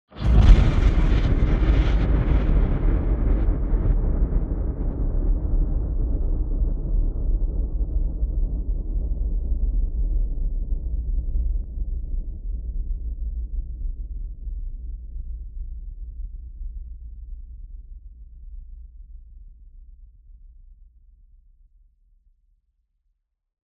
دانلود آهنگ رعدو برق 8 از افکت صوتی طبیعت و محیط
جلوه های صوتی
دانلود صدای رعدو برق 8 از ساعد نیوز با لینک مستقیم و کیفیت بالا